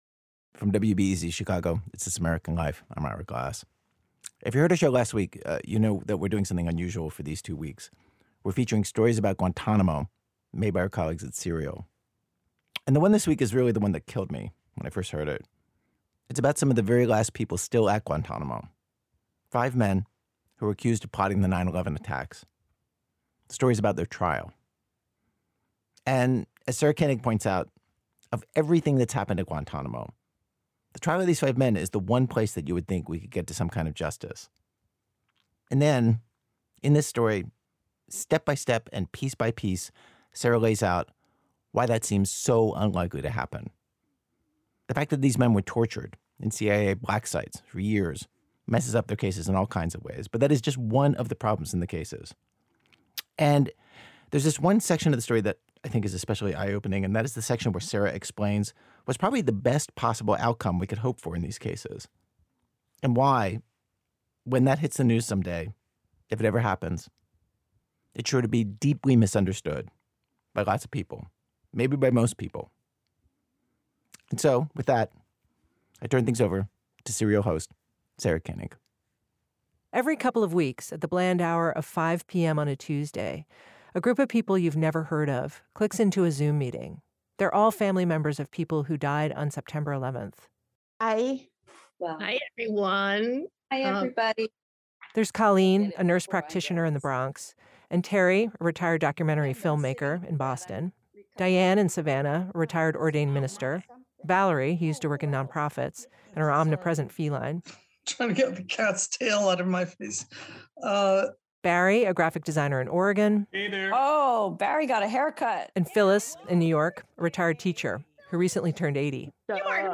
Note: The internet version of this episode contains un-beeped curse words.
Host Ira Glass introduces the new series that Serial is doing about Guantánamo Bay.